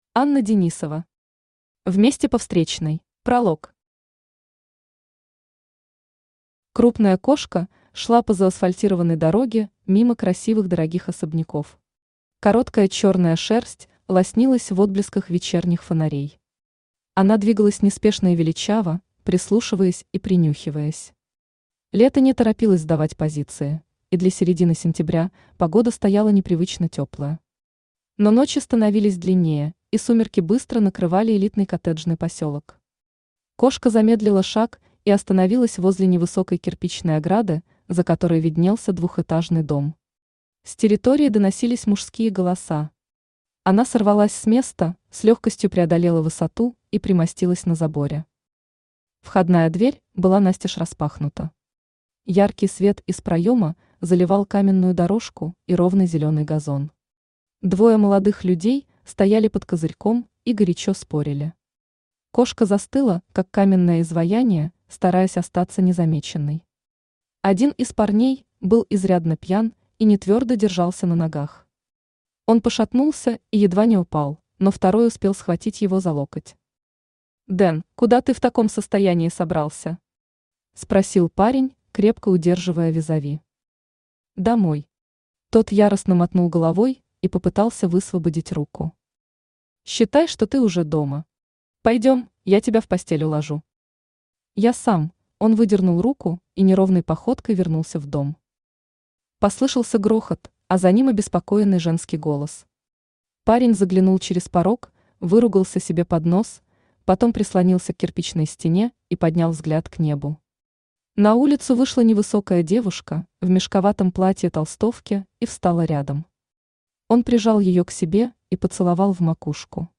Аудиокнига Вместе по встречной | Библиотека аудиокниг
Aудиокнига Вместе по встречной Автор Анна Юрьевна Денисова Читает аудиокнигу Авточтец ЛитРес.